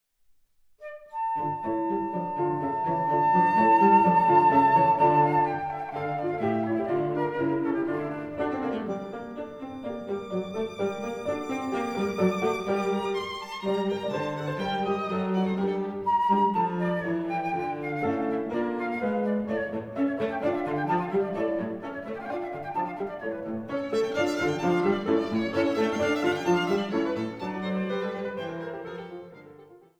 Flöte
Violoncello
Klavier
Das Cembalo wird durch das Klavier ersetzt.